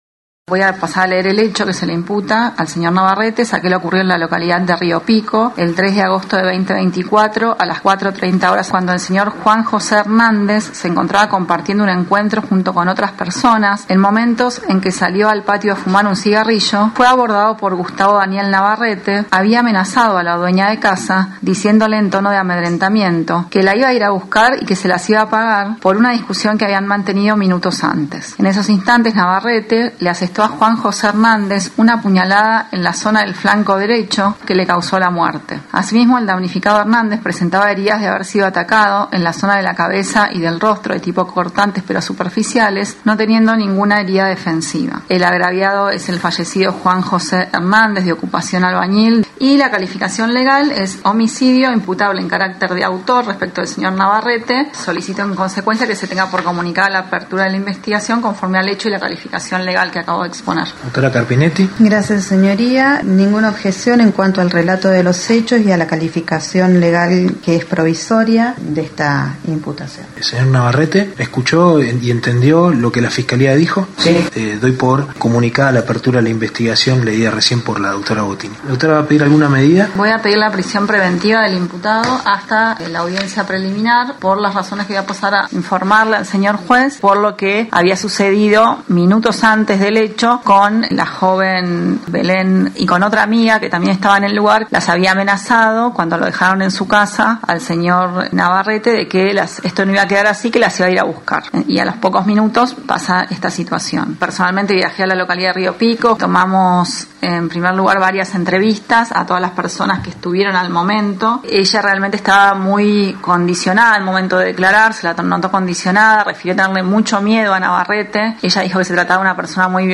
En los tribunales de Esquel, se realizó la audiencia para controlar la detención del acusado por un hecho de homicidio que se produjo en la localidad de Río Pico, en el marco de un festejo denominado “after” (fiesta que se celebra después del boliche).
Durante la audiencia la Fiscalía dio a conocer cual es el hecho